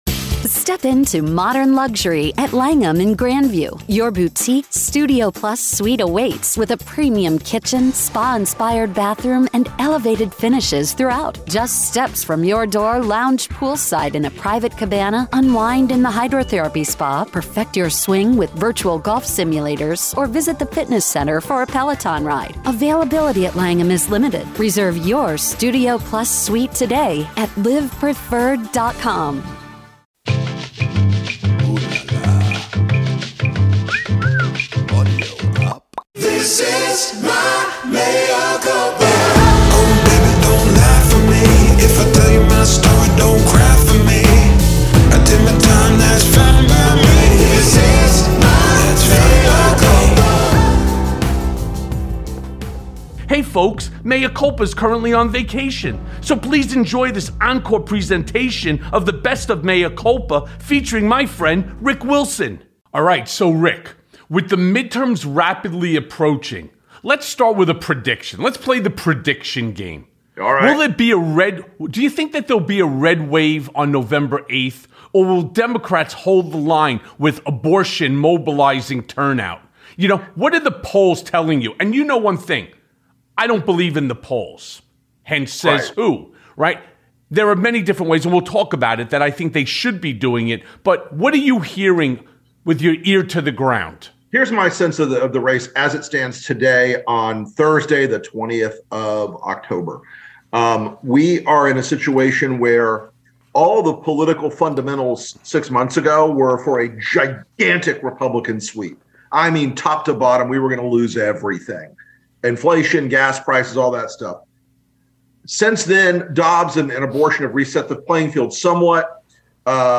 So, let’s go now to that conversation.